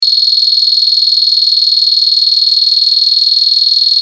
SIRENA 8 TONOS
Sirena con 8 tonos diferentes
Tono_7